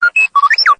Звуки дроида R2D2 из звёздных войн в mp3 формате
4. Звук r2d2 на смс